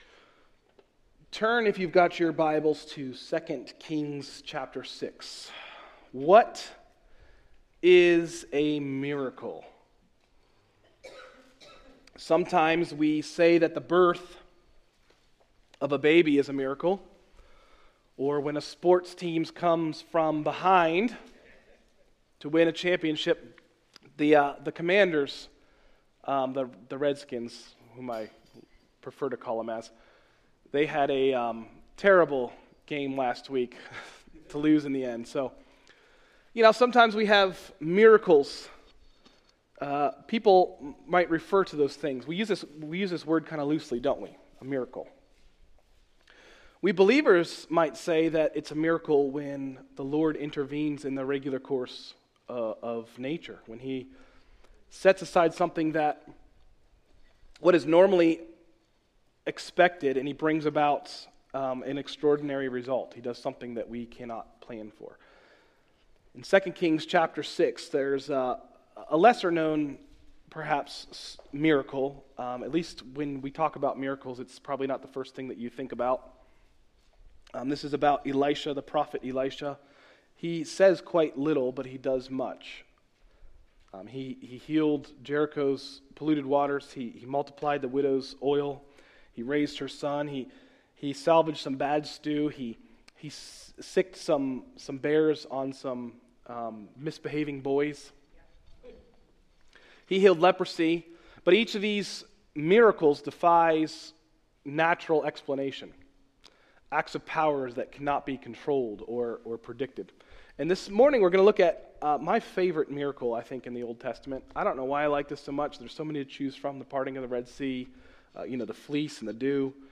Message: “God Cares” – Tried Stone Christian Center
This sermon is to be a reminder of how God cares for even the little things we face.